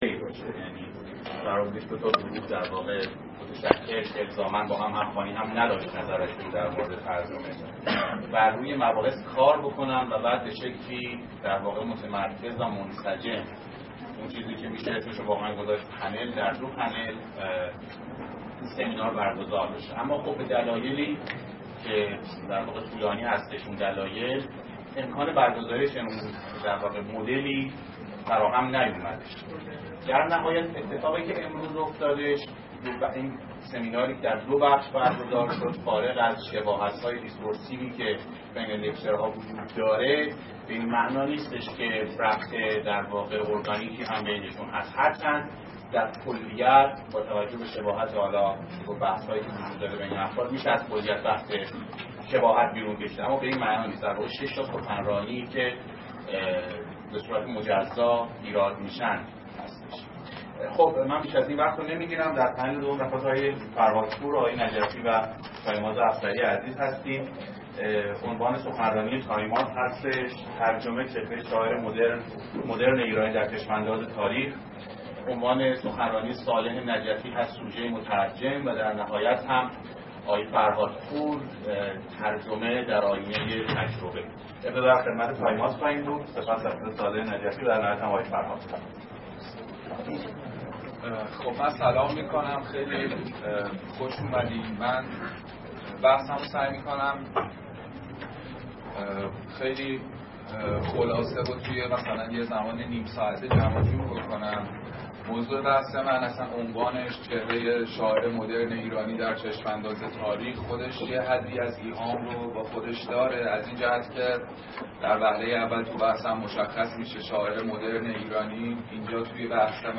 سخنرانی
در سمینار وضعیت ترجمه در ایران است که در مؤسسه مطالعات سیاسی اقتصادی پرسش برگزار شد.